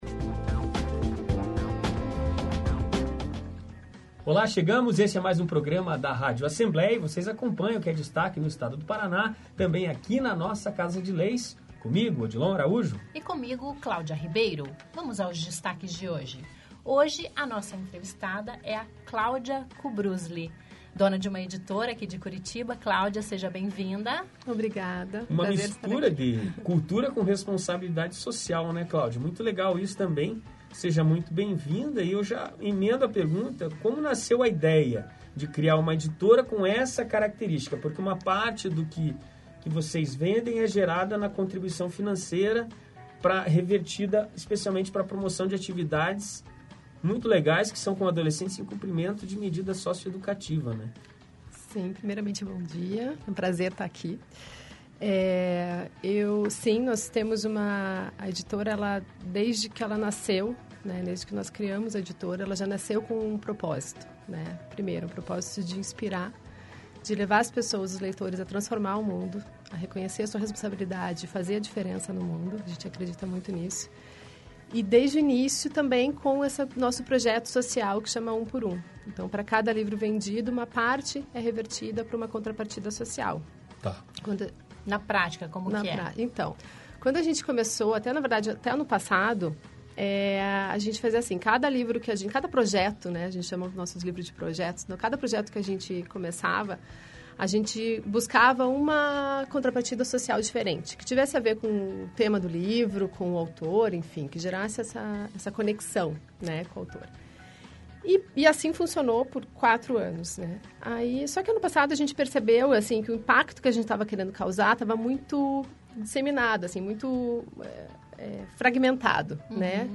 Uma entrevista inspiradora sobre como aliar empreendedorismo e responsabilidade
Confira matéria no site, entrevista em áudio e em vídeo.